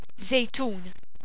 Aussprache )